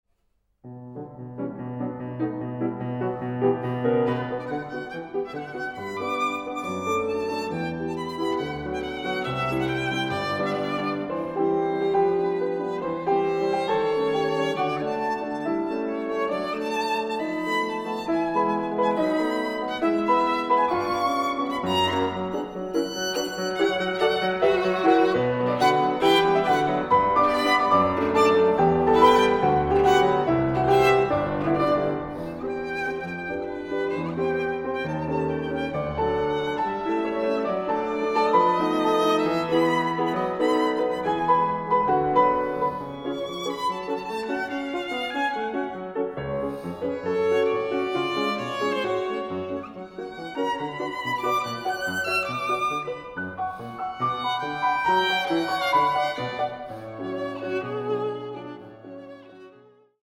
Sonatas for Piano and Violin
Piano
Violin